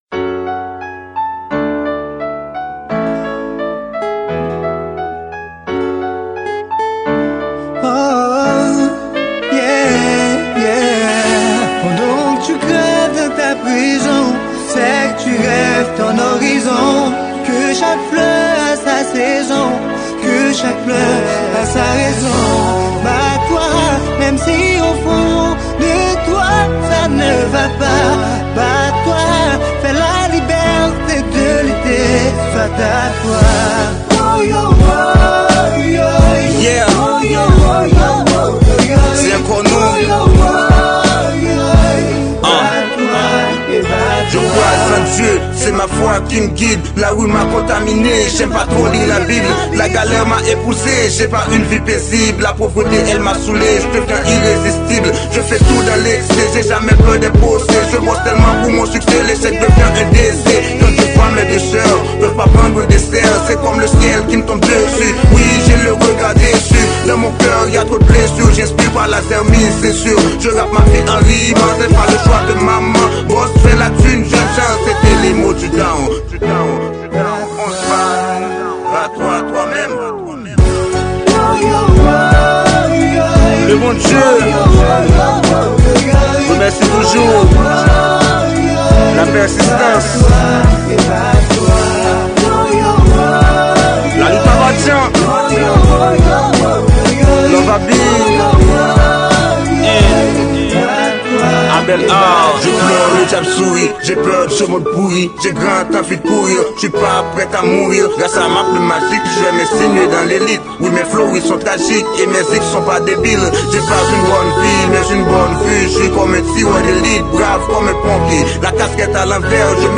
Genre: Rap & Rnb